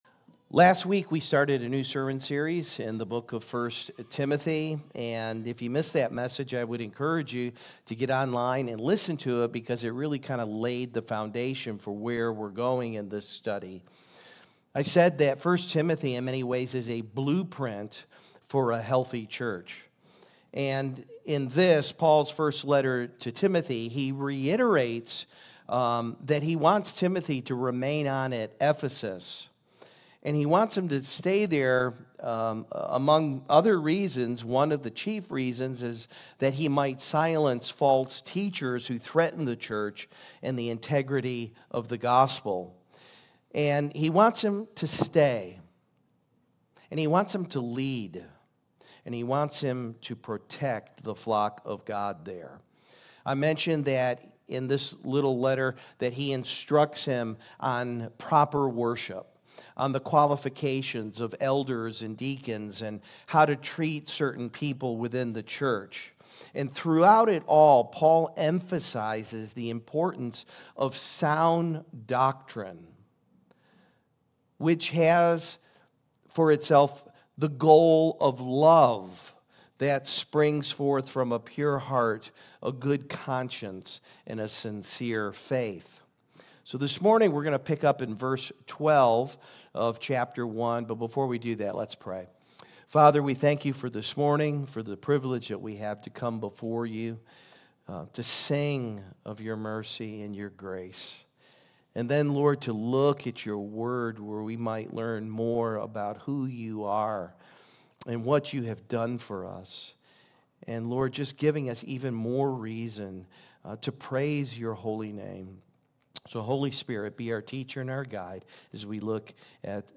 — Billy Graham Check out this week’s message as we take a brief excursus from 1st Timothy to explore the legacy of Eunice and Lois, the mother and grandmother of Timot…